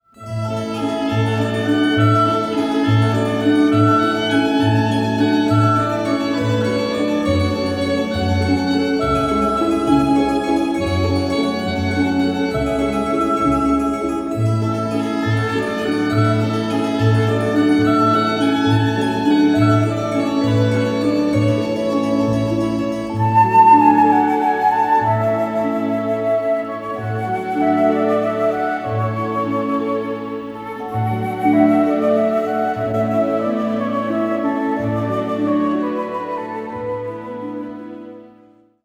a delicate and poetic score
Remastered from the scoring session tapes